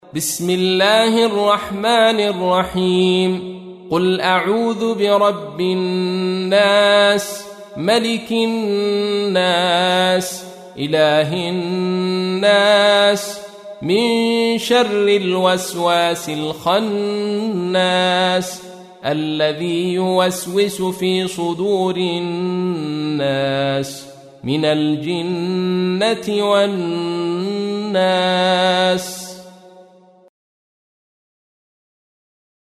تحميل : 114. سورة الناس / القارئ عبد الرشيد صوفي / القرآن الكريم / موقع يا حسين